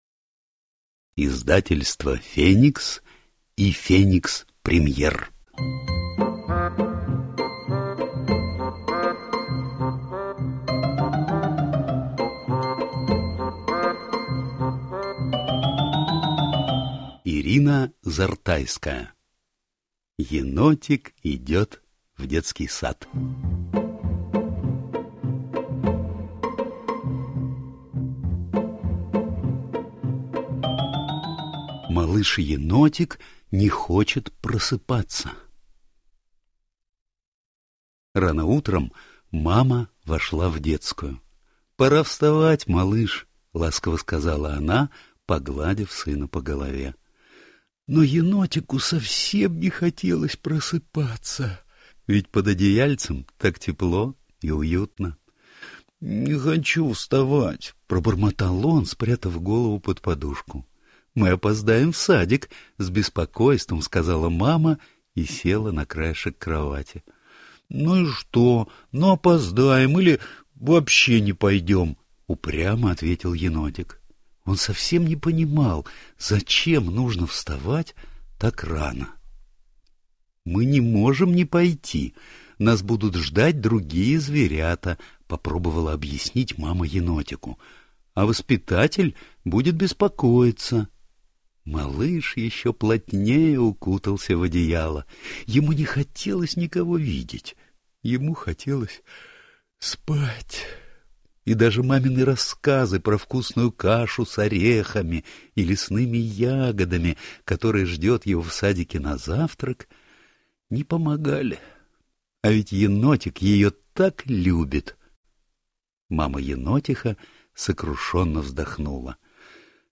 Аудиокнига Енотик идет в детский сад | Библиотека аудиокниг